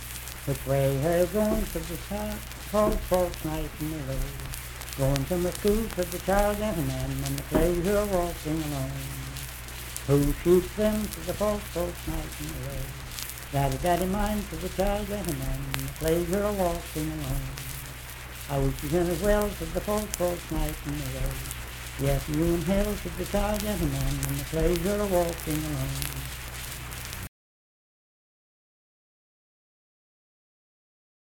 Unaccompanied vocal music performance
Verse-refrain 3(4w/R).
Voice (sung)